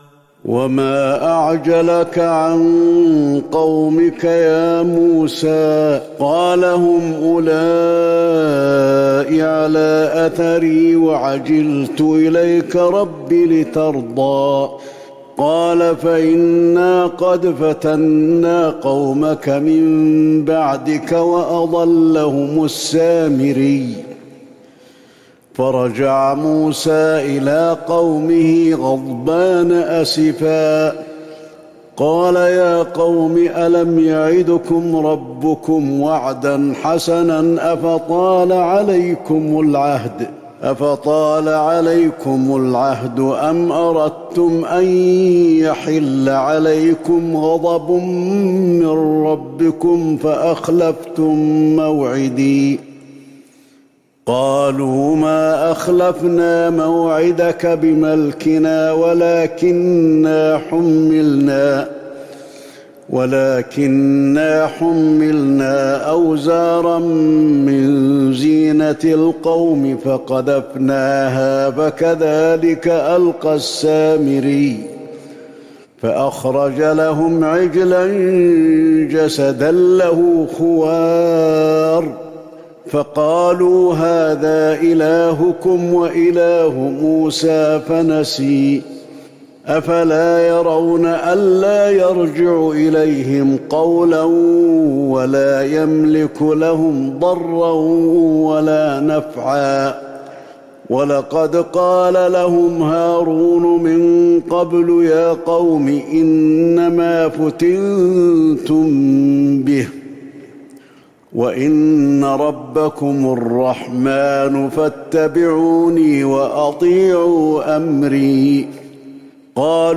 تهجد ليلة ٢١ رمضان ١٤٤١هـ من سورة طه { ٨٣-١١٤ } > تراويح الحرم النبوي عام 1441 🕌 > التراويح - تلاوات الحرمين